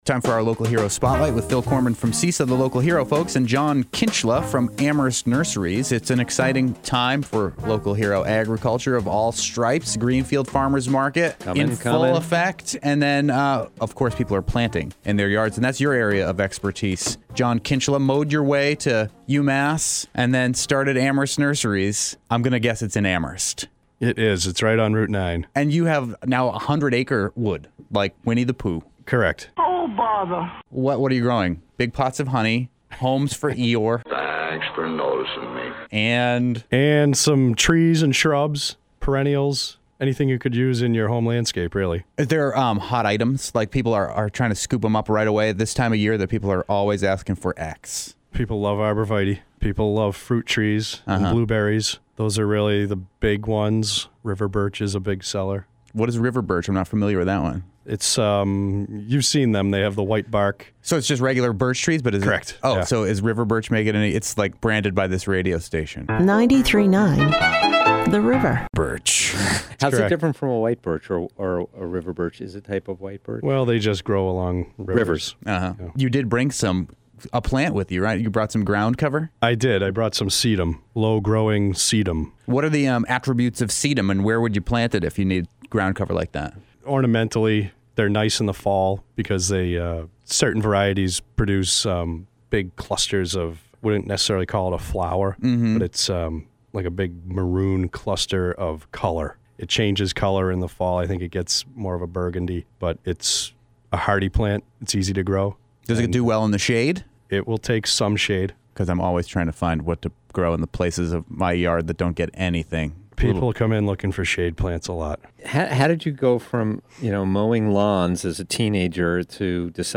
Interview: Amherst Nurseries